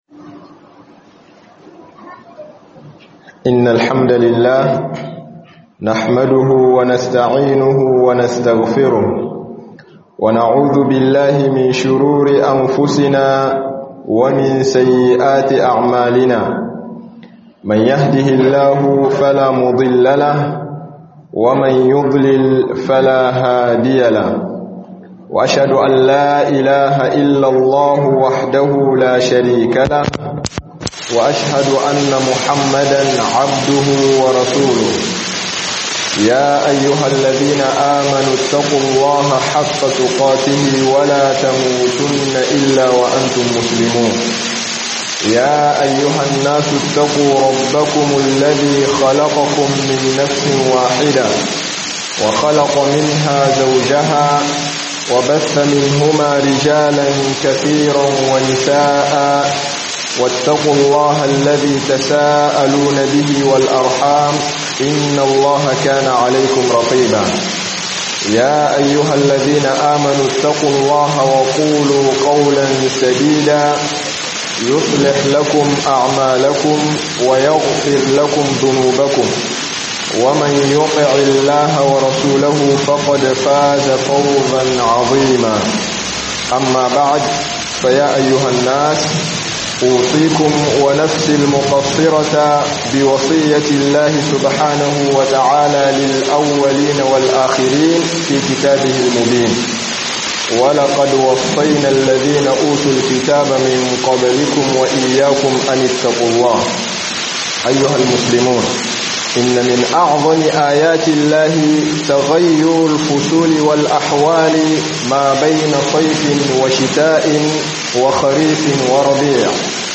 Kwaɗaitarwa akan yin Azumi da kiyamullali - Hudubobi